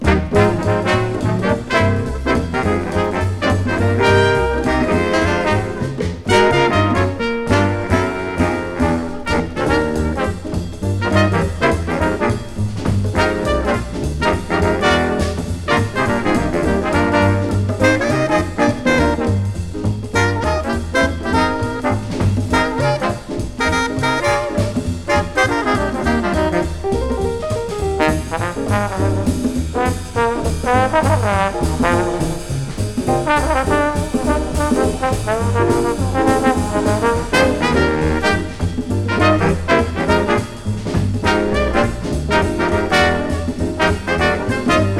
通して演奏アレンジ良くポップな聴き心地で、小粋なモダンジャズを展開。
Jazz　USA　12inchレコード　33rpm　Mono